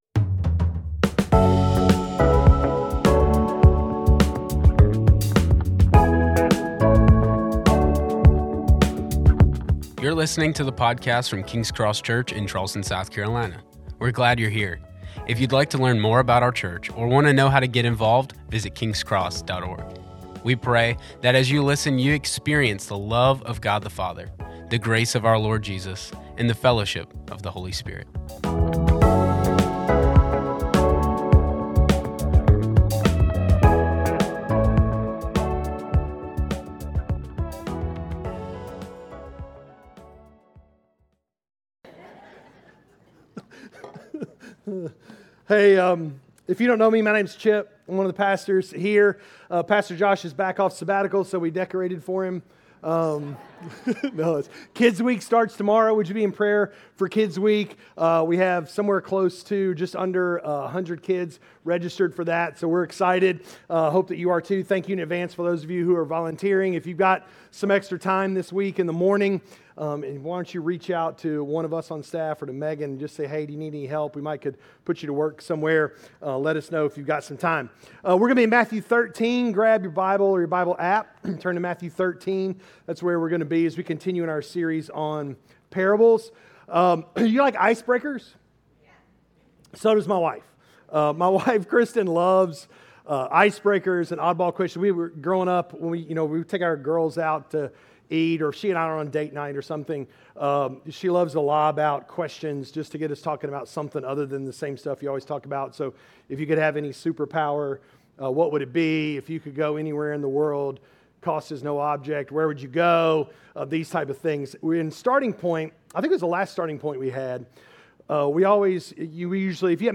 A message from the series "Parables."